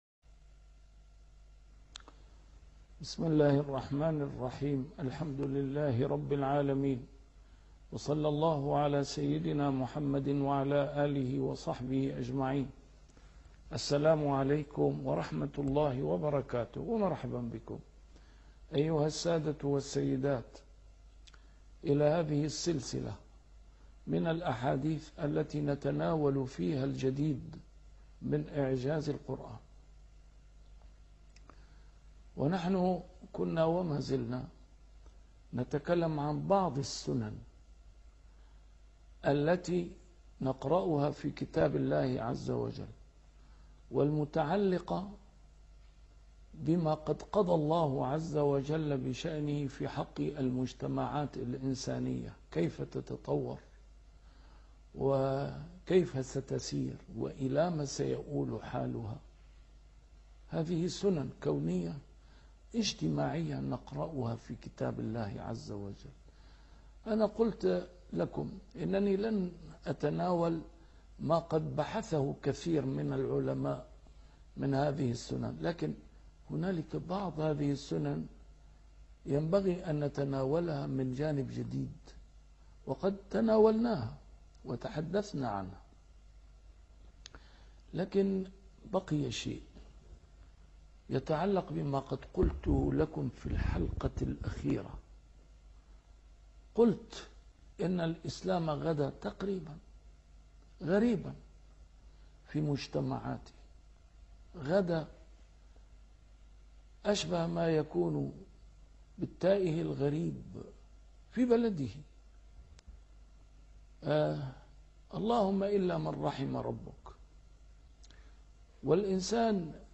A MARTYR SCHOLAR: IMAM MUHAMMAD SAEED RAMADAN AL-BOUTI - الدروس العلمية - الجديد في إعجاز القرآن الكريم - 22- الجديد في إعجاز القرآن الكريم |سنن كونية تحققت